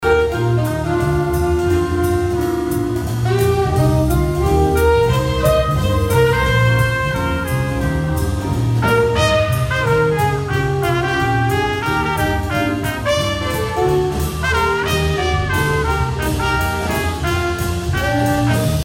The department hosted its annual Music Gala Benefit Concert inside of Albert Taylor Hall.
jazz-1-esu-music-gala.mp3